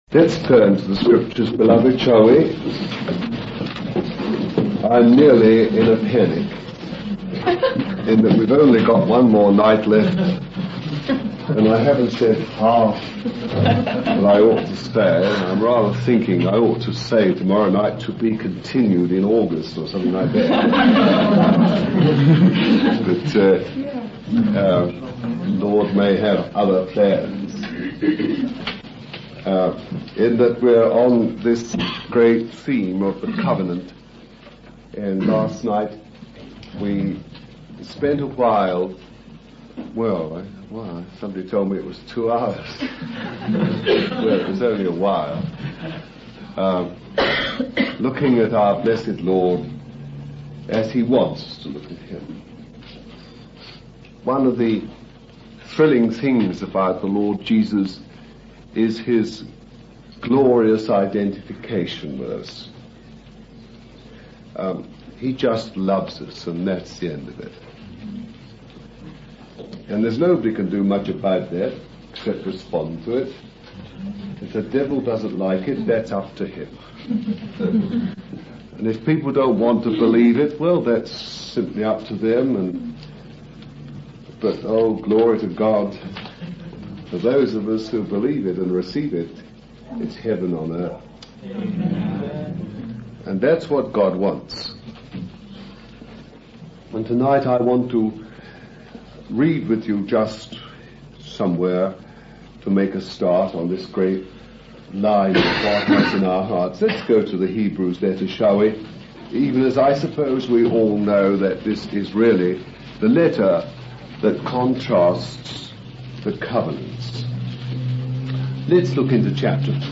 In this sermon, the preacher starts off by expressing gratitude to the Lord and emphasizing the importance of starting on a positive note. He then refers to a verse from the Bible that talks about the Lord refining and purifying his people.